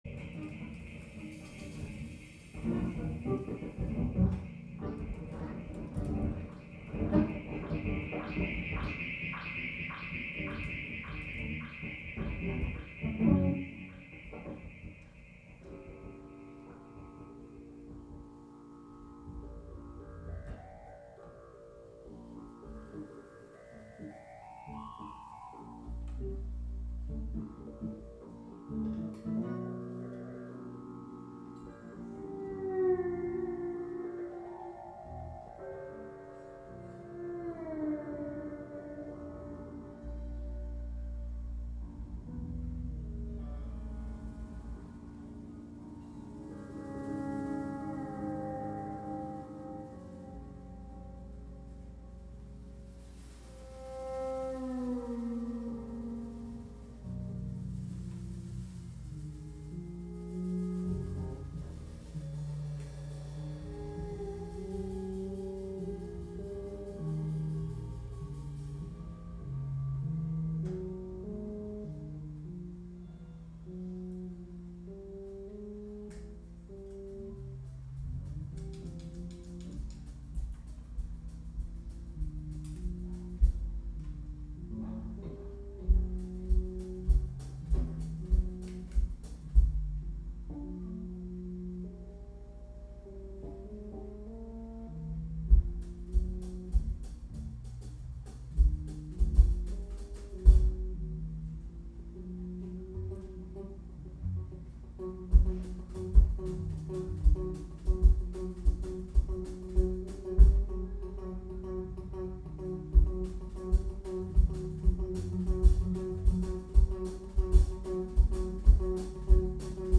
From a live webstream at The Avalon Lounge.